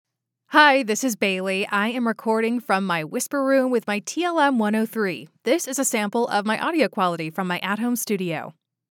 Female
English (North American)
Yng Adult (18-29), Adult (30-50)
Studio Quality Sample